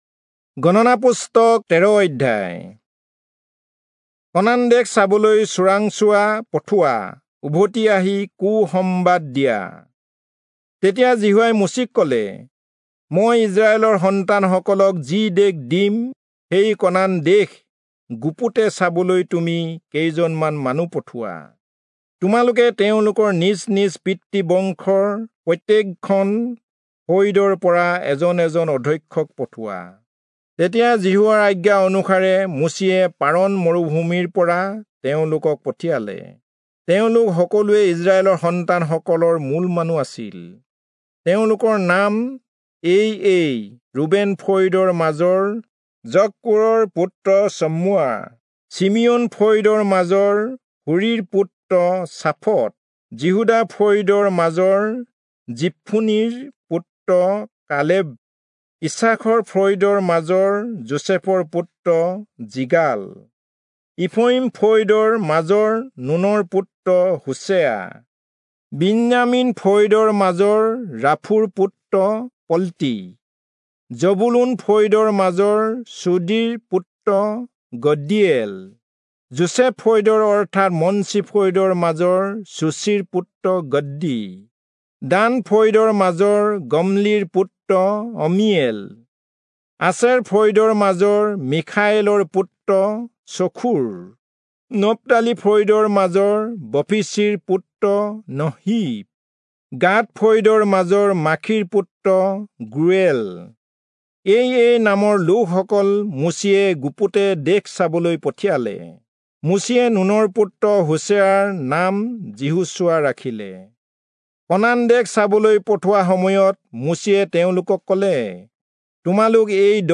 Assamese Audio Bible - Numbers 33 in Irvta bible version